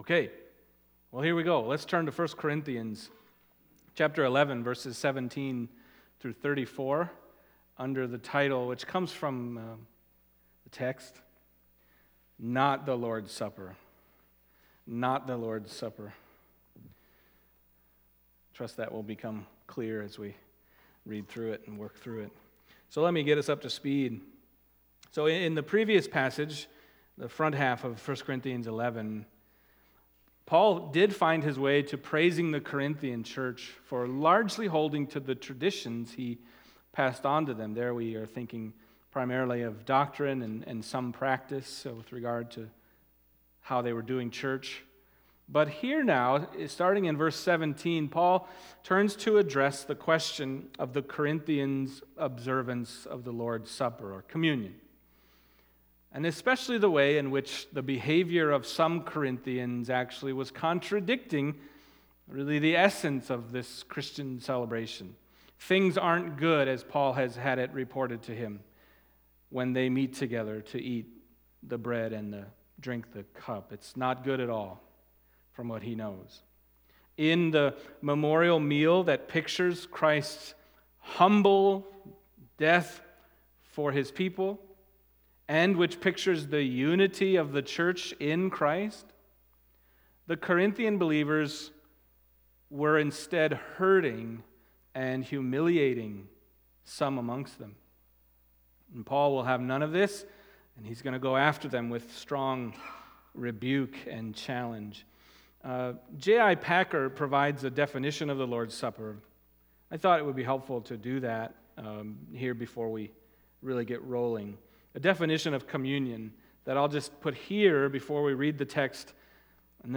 Passage: 1 Corinthians 11:17-34 Service Type: Sunday Morning